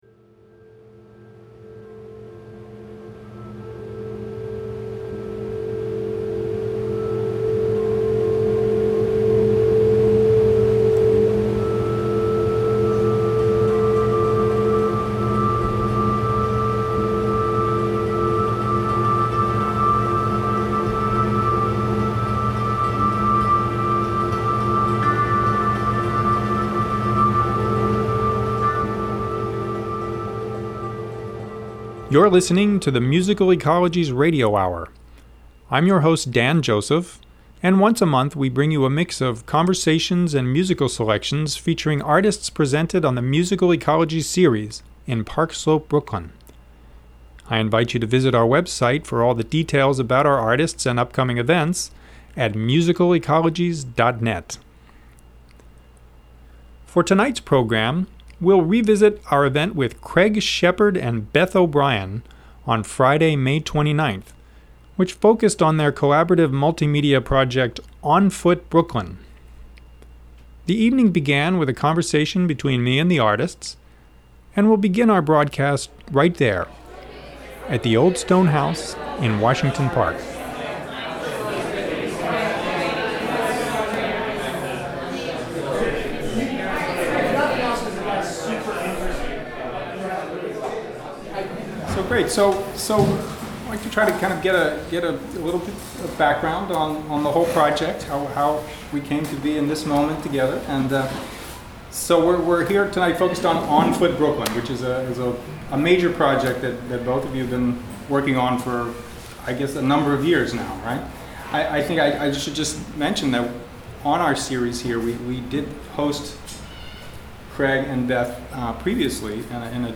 The Musical Ecologies Radio Hour features conversations, live concert recordings, and other audio excerpts featuring artists presented on the Musical Ecologies series in Park Slope, Brooklyn.